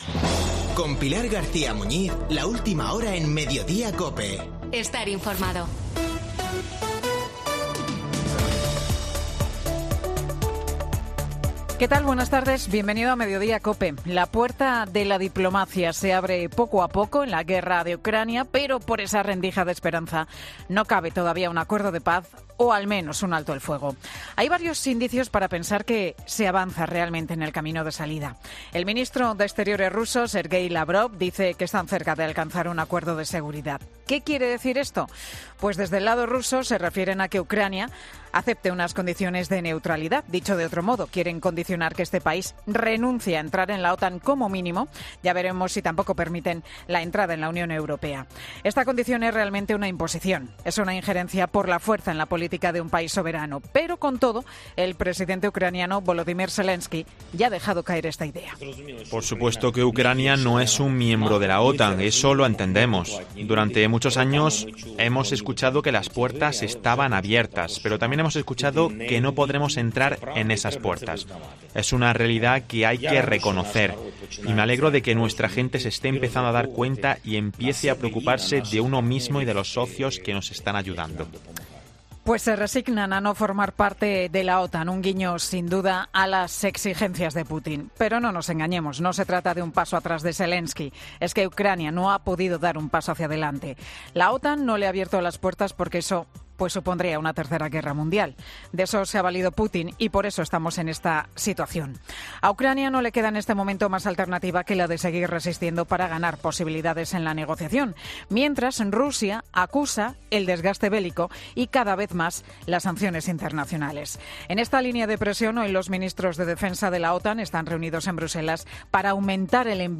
El monólogo de Pilar García Muñiz, en Mediodía COPE